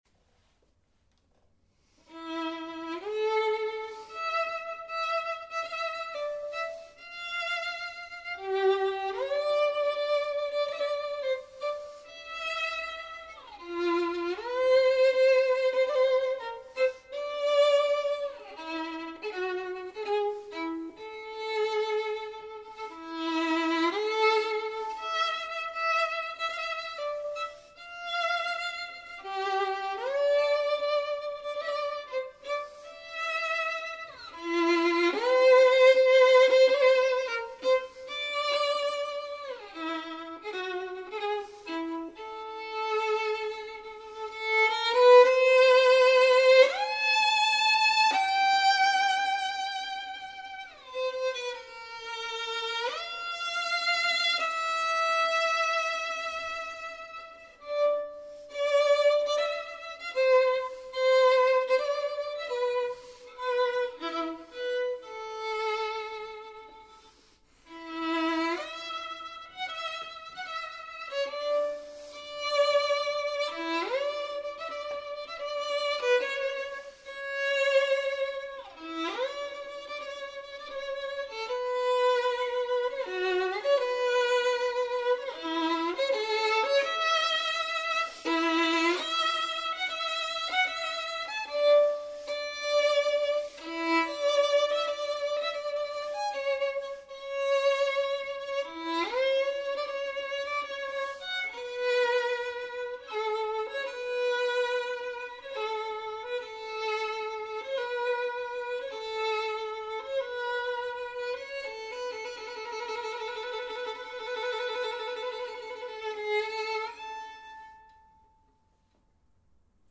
小提琴
*?请使用高品质耳机收听,作品录音无任何后期加工
喜爱之余，再加上她性感的声音，就命名为“黑丝”了。